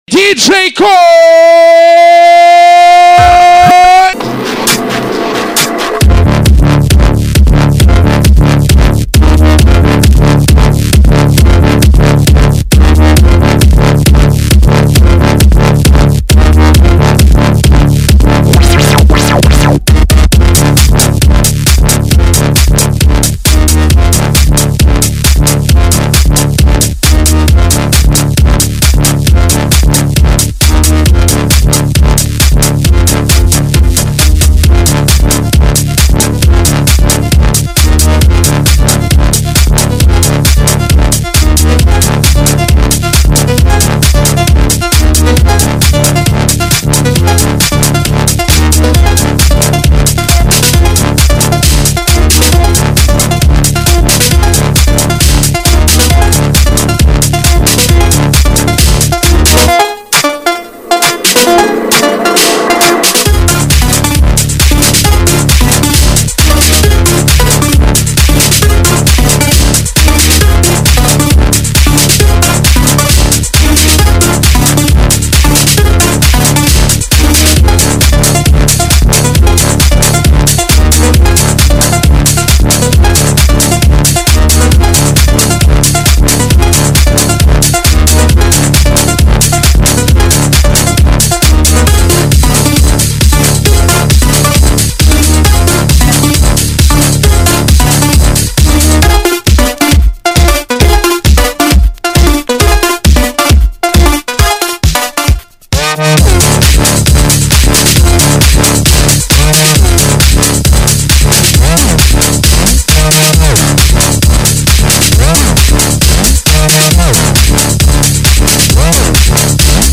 Список файлов рубрики Клубная